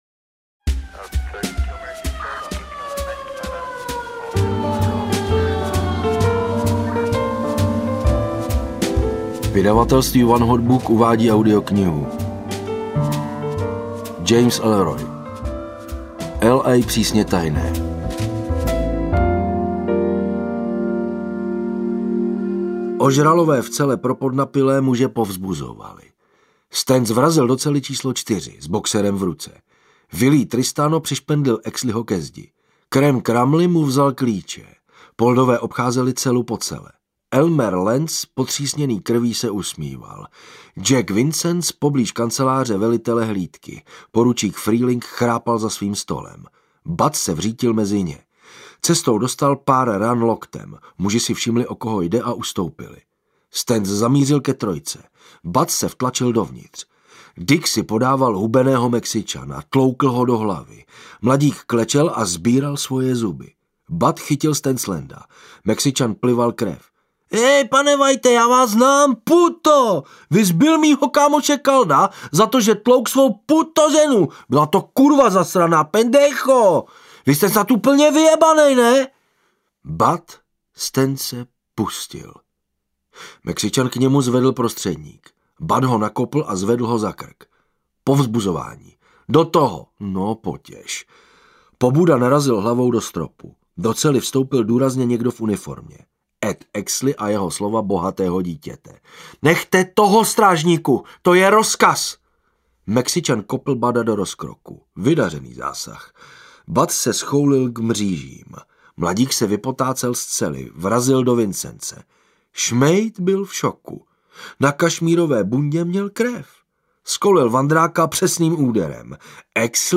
L. A. Přísně tajné audiokniha
Ukázka z knihy